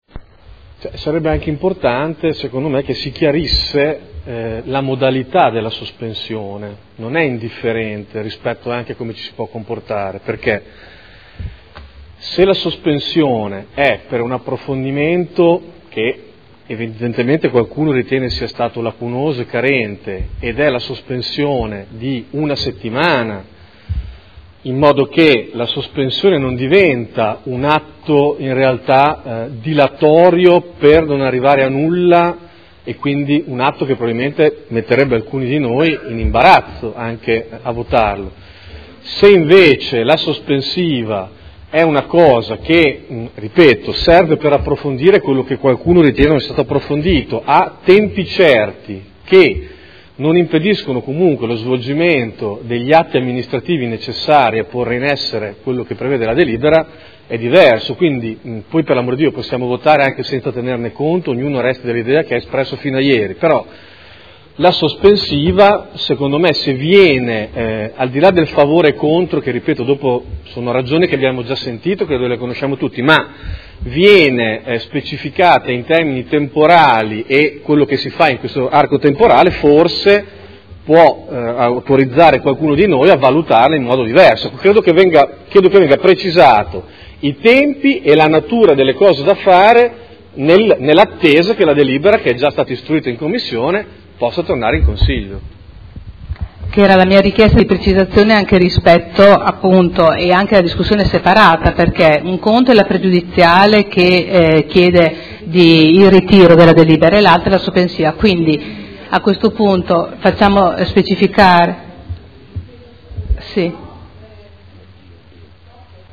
Davide Torrini — Sito Audio Consiglio Comunale
Dibattito su pregiudiziale alla proposta di deliberazione. Nuovo Mercato Ortofrutticolo all’Ingrosso